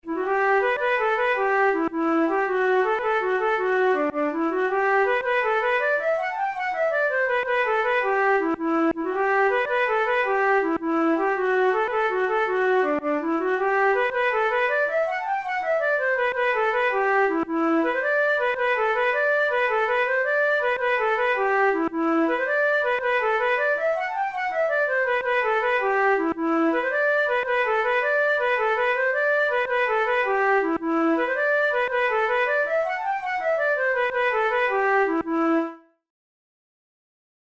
InstrumentationFlute solo
KeyG major
Time signature6/8
Tempo108 BPM
Jigs, Traditional/Folk
Traditional Irish jig